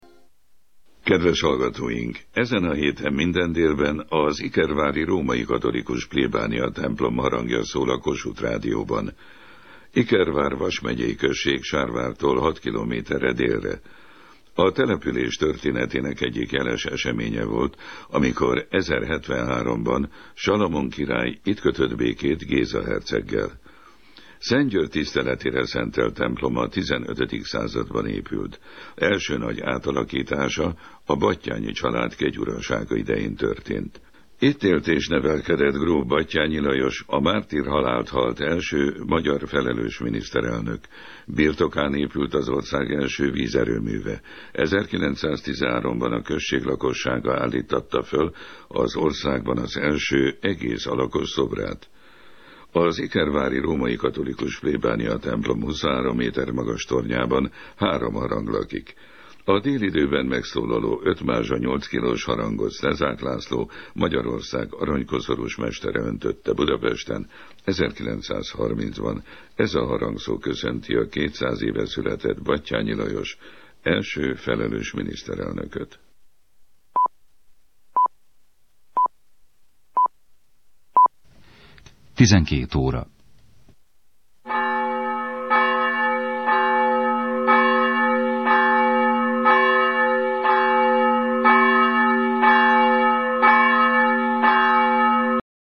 Ikervári templom harangjainak hangjait is megidézném. A 72 cm átmérőjű nagy harangot 1893-ban öntötte Wiener Neustadtban Hikzer Peter.
déli harangszóját az ikervári templom szolgáltatta.
deli_harangszo_kossuth_radio_2007_8_het.mp3